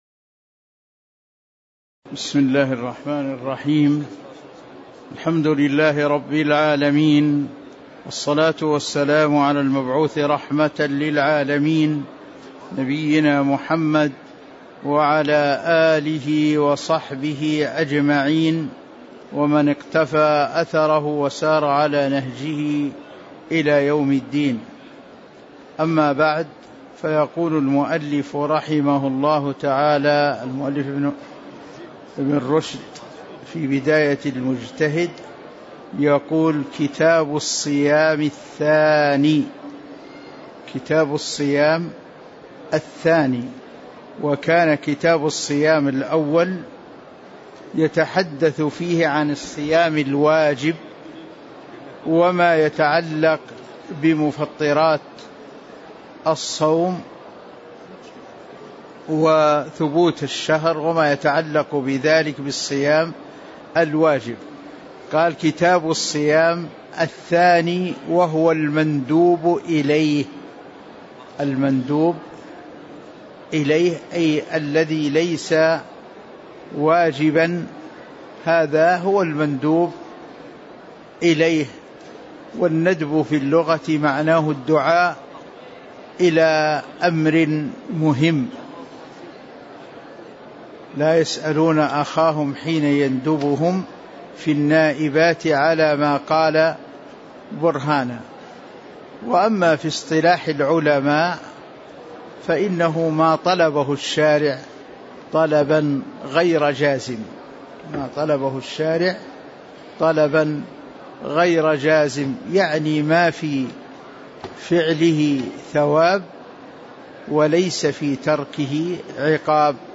تاريخ النشر ١٢ رجب ١٤٤٦ هـ المكان: المسجد النبوي الشيخ